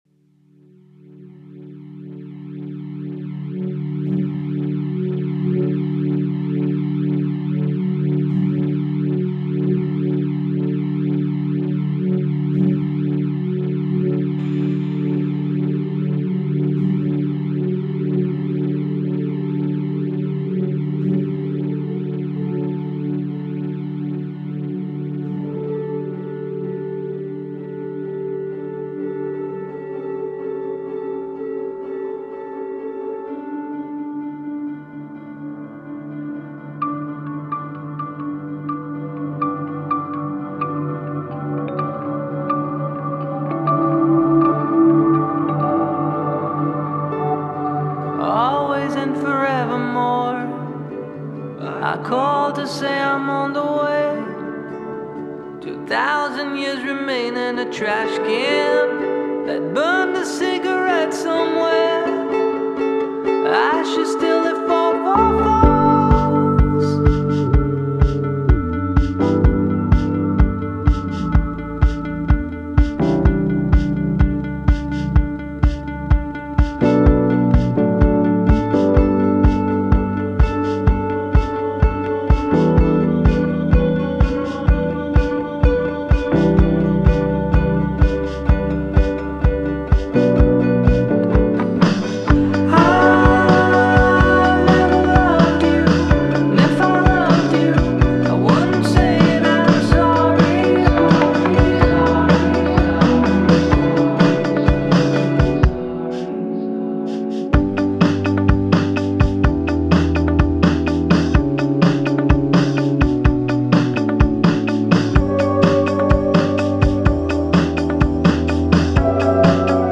remix
a mellow, meditative one